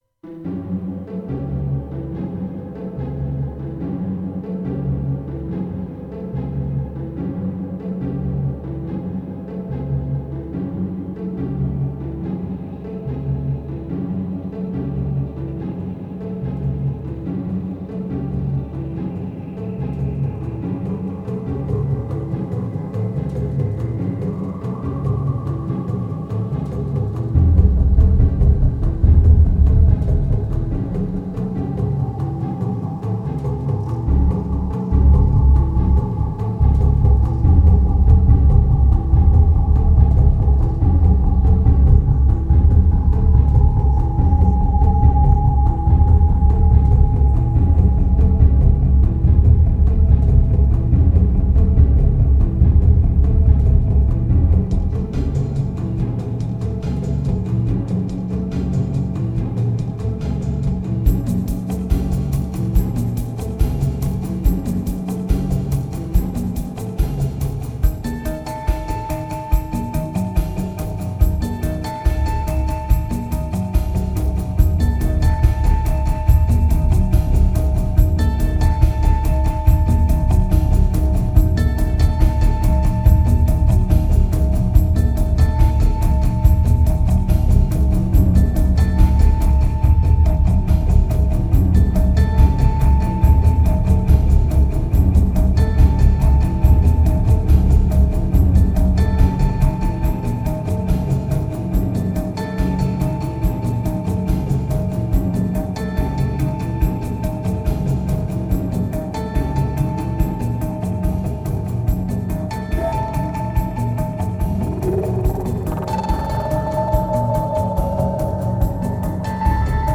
2006📈 - -2%🤔 - 143BPM🔊 - 2010-12-05📅 - -174🌟